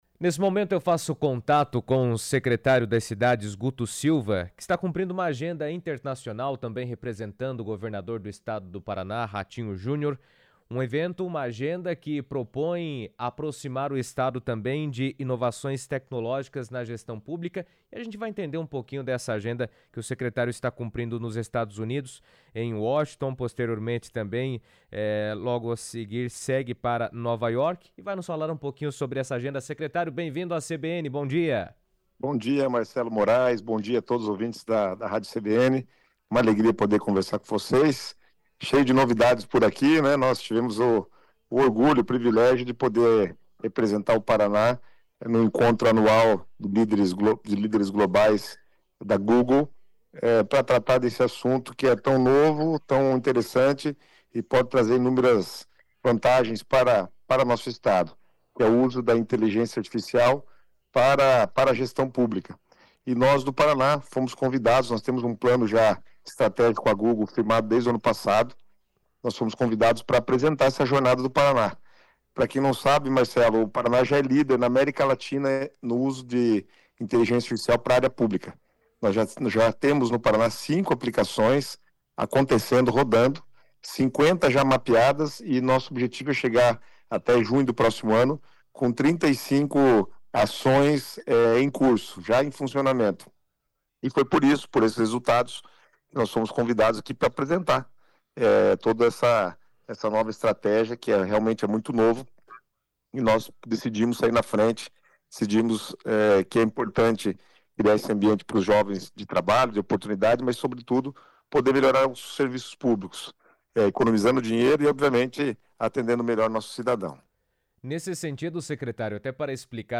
Guto Silva, secretário do Paraná, conversou por telefone com a CBN direto dos Estados Unidos, onde representa o estado em compromissos com a Google Cloud e a ONU-Habitat. Durante a entrevista, ele destacou a importância dessas parcerias internacionais para fortalecer a inovação tecnológica e o desenvolvimento urbano sustentável no Paraná.